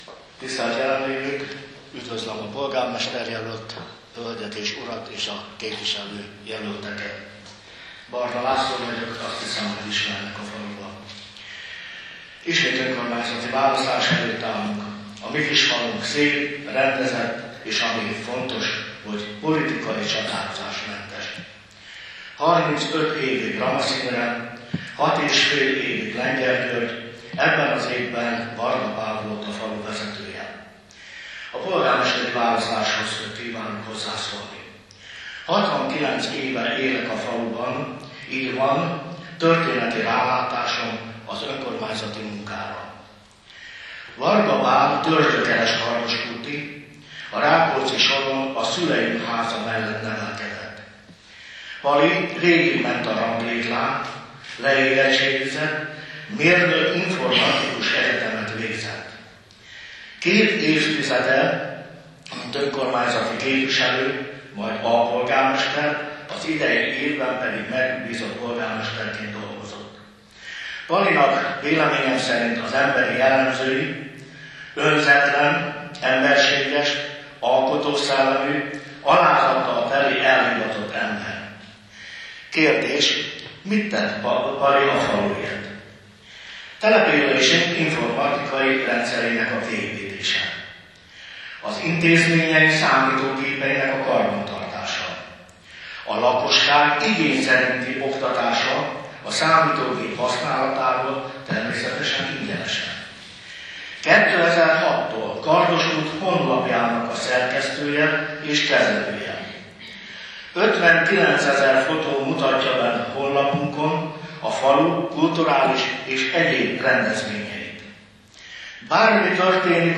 2019. október 2-án bemutatkoztak az érdeklődők előtt a polgármester- és képviselő jelöltek. Alábbiakban meghallgathatják a vágatlan felvételeket az elhangzás sorrendjében.